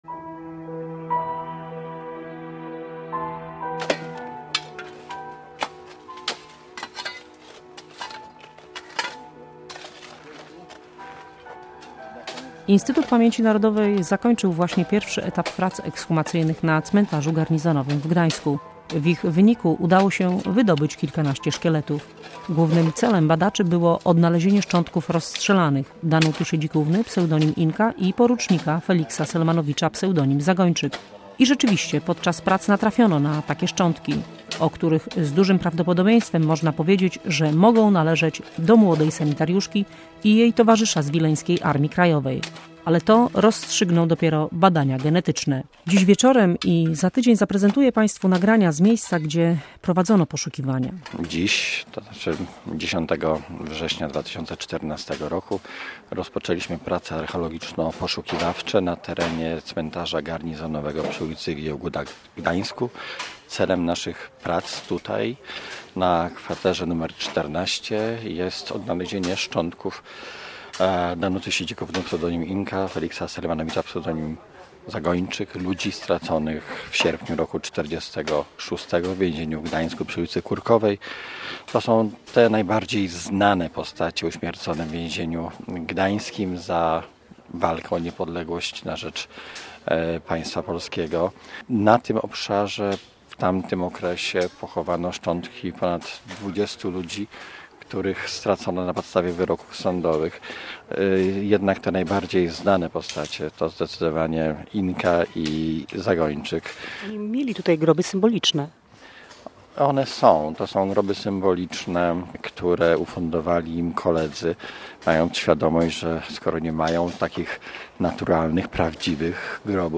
Ukryta prawda. Część pierwsza audycji dokumentalnej